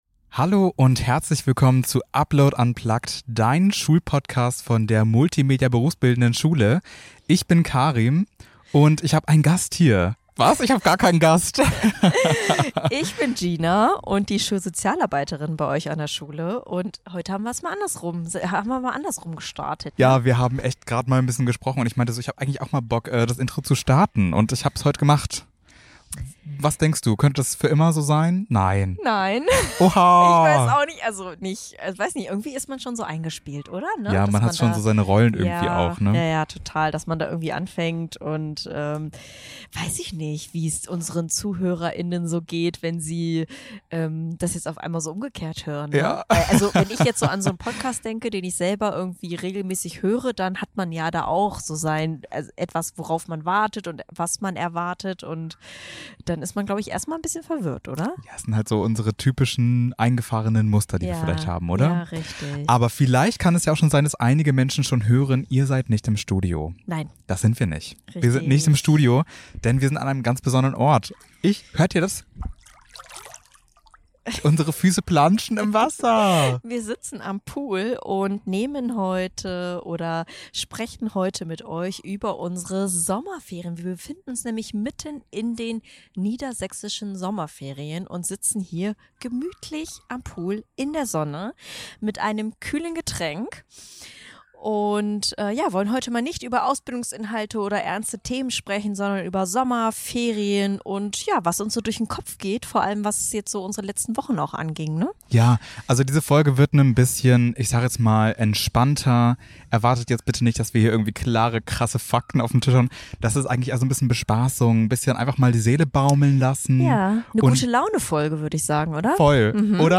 In dieser besonderen Ferienfolge von Upload Unplugged - Zwischen Laptop und Lebensfragen haben wir den Aufnahmeort kurzerhand in den Garten verlegt. Mit einem kalten Getränk in der Hand blicken wir zurück auf vergangene Folgen, erzählen von unseren Sommerplänen.